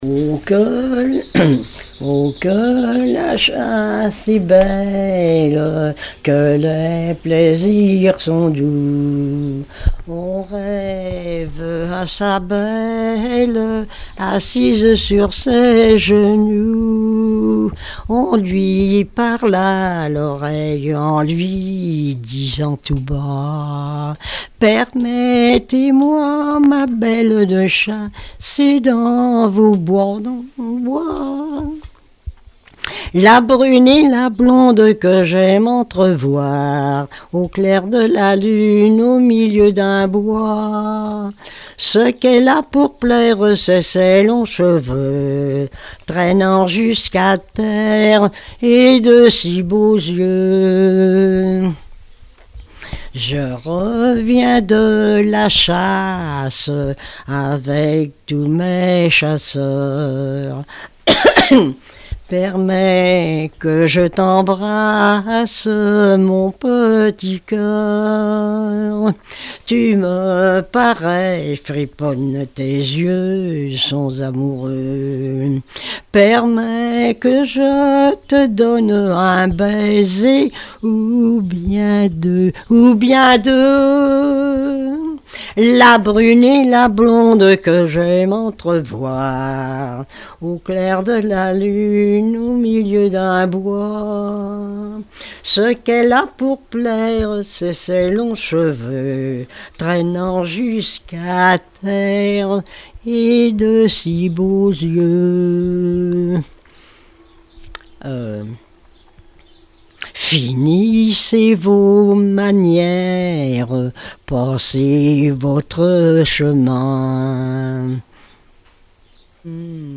Genre strophique
témoigneges et chansons populaires
Catégorie Pièce musicale inédite